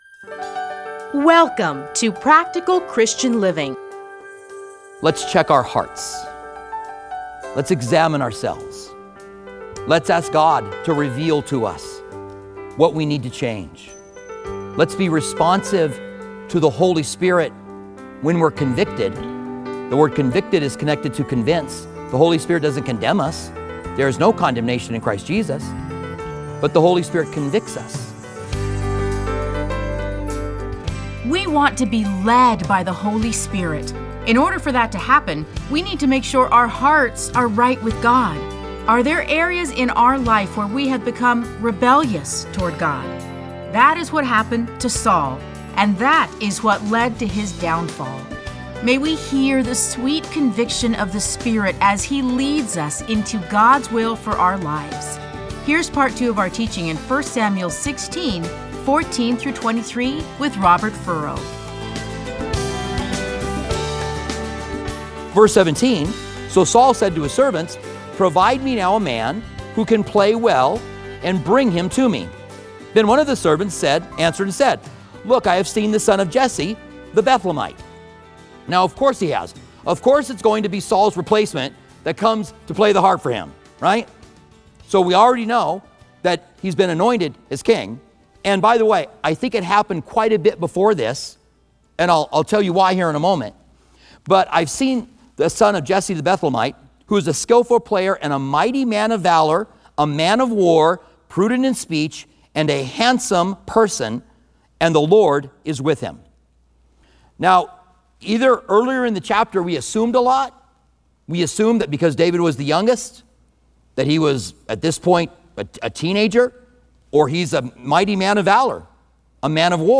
Listen to a teaching from 1 Samuel 16:14-23.